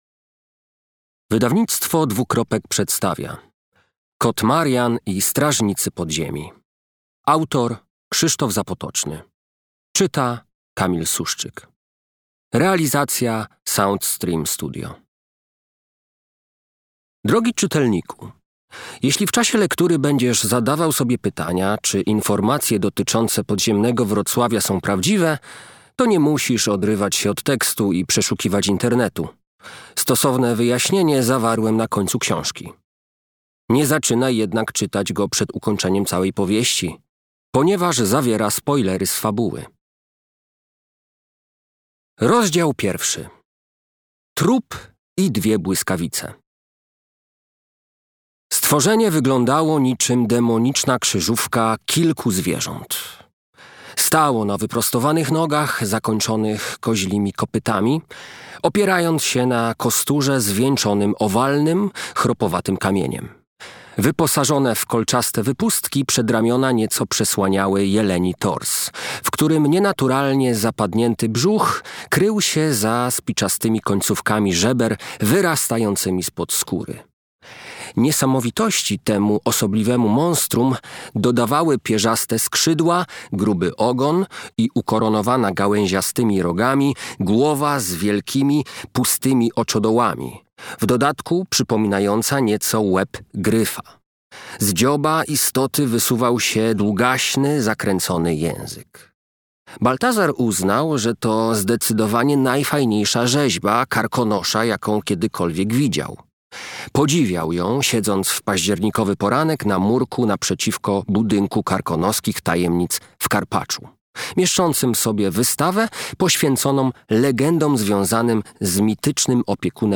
Kot Marian i strażnicy podziemi - Krzysztof Zapotoczny - audiobook